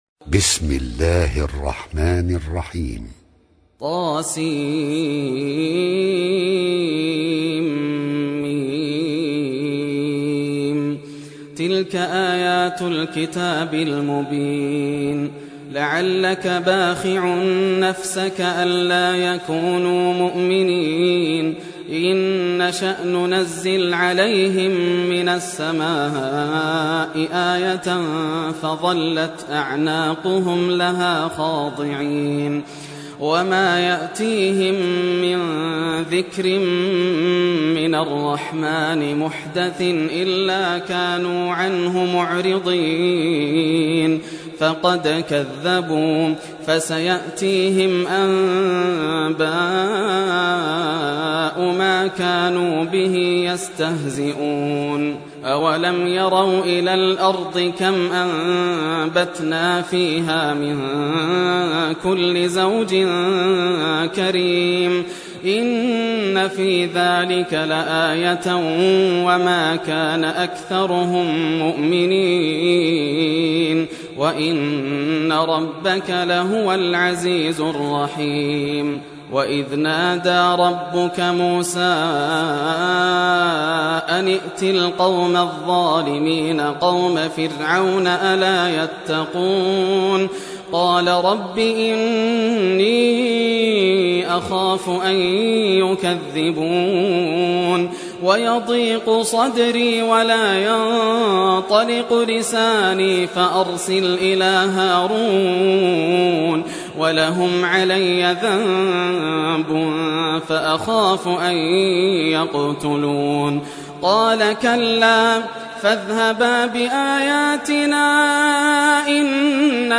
Surah Ash-Shuara Recitation by Yasser al Dosari
Surah Ash-Shuara, listen or play online mp3 tilawat / recitation in Arabic in the beautiful voice of Sheikh Yasser al Dosari.